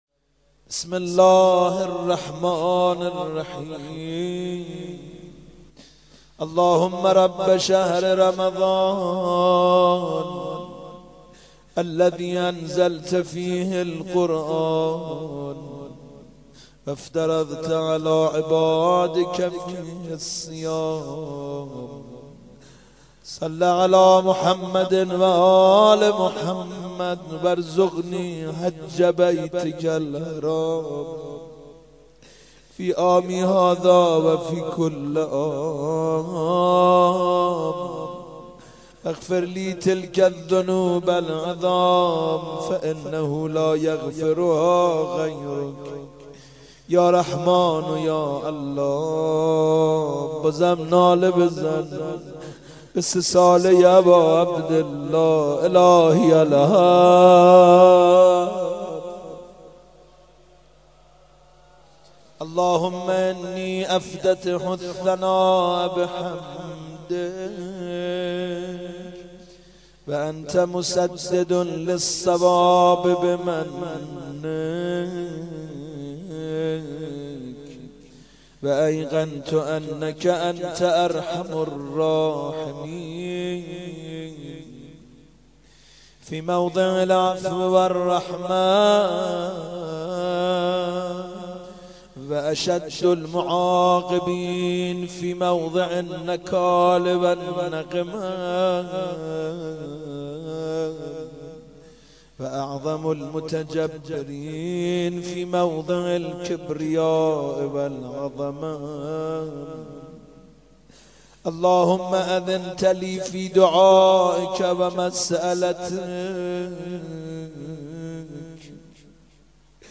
آمده ماه خدا حضرت دلدار بیا - مناجات فرازهایی از دعای افتتاح لینک کپی شد گزارش خطا پسندها 0 اشتراک گذاری فیسبوک سروش واتس‌اپ لینکدین توییتر تلگرام اشتراک گذاری فیسبوک سروش واتس‌اپ لینکدین توییتر تلگرام